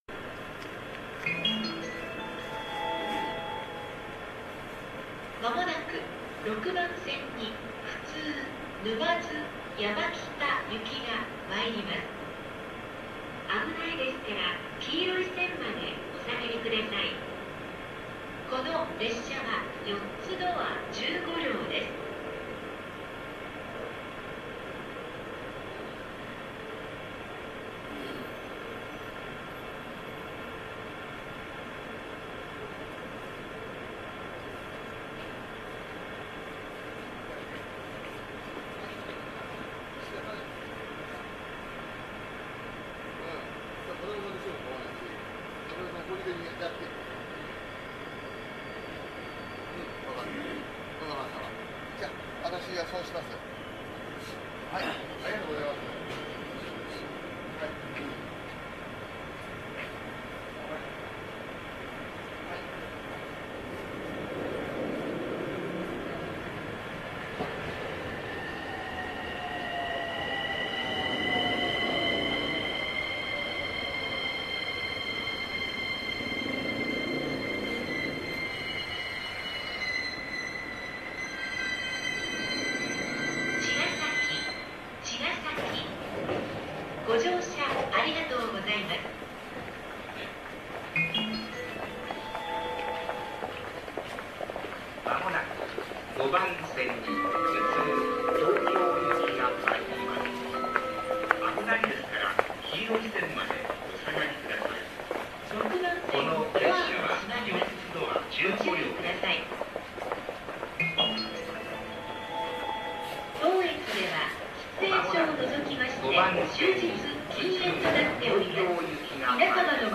走行音収録の合間などに録った音、偶然マイクに入った音などを、特に意味もなく公開していきます。
やはり113系のような重みは全くありませんね。
なお今回も同じようなタイミングで上りも入ってきています。
なお途中で貨物列車が友情出演してます。
茅ヶ崎駅E231系発着風景